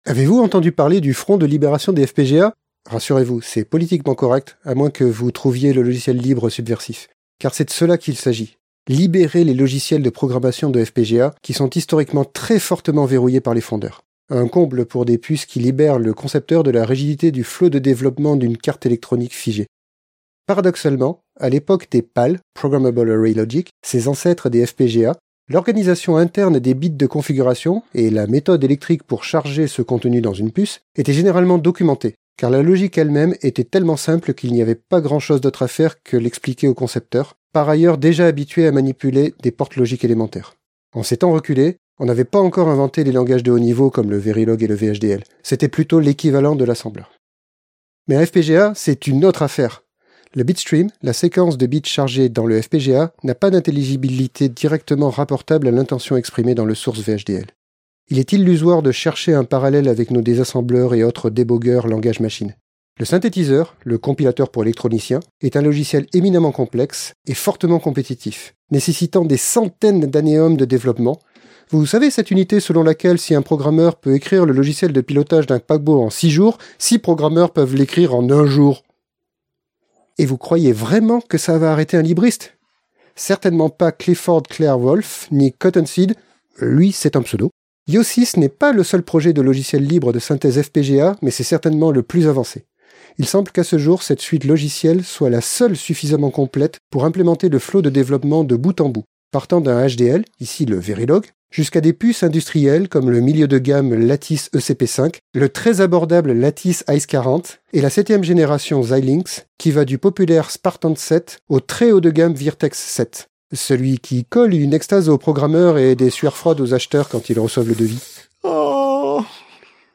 Extrait de l'émission CPU release Ex0233 : FPGA, deuxième partie.